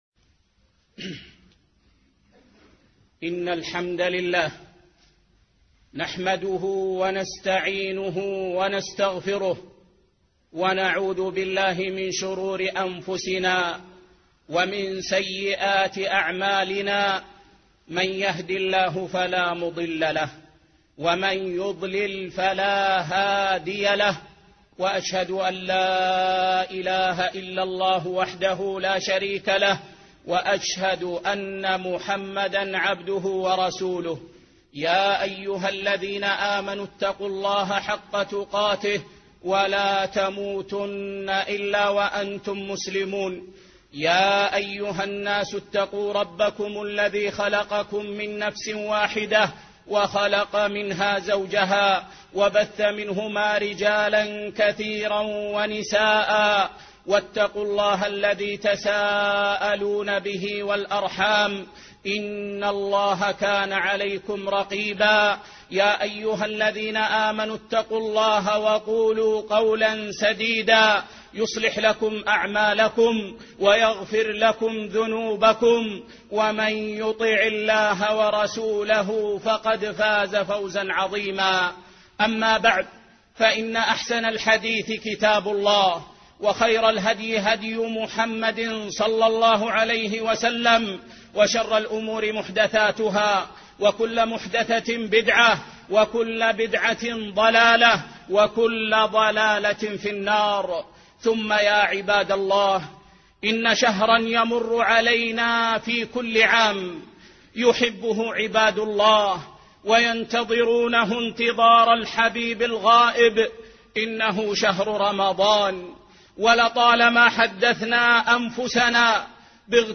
الطاعة في رمضان - خطبة